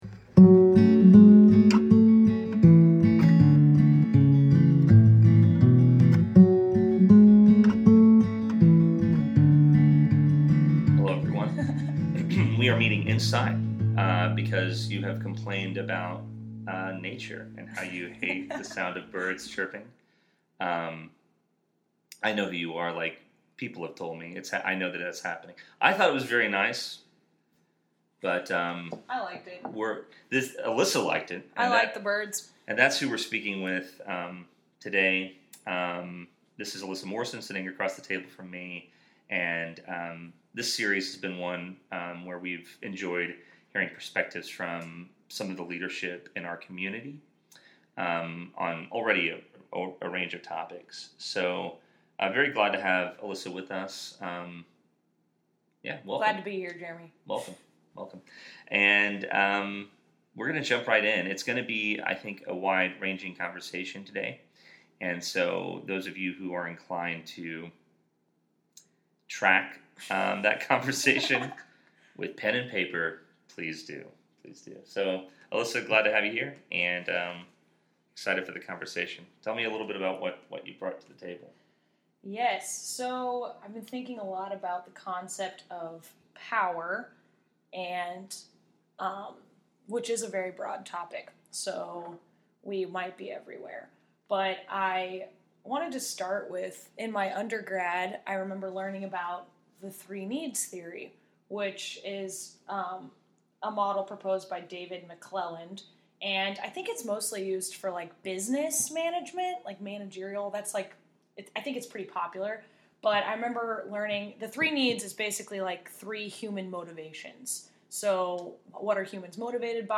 Power | A Conversation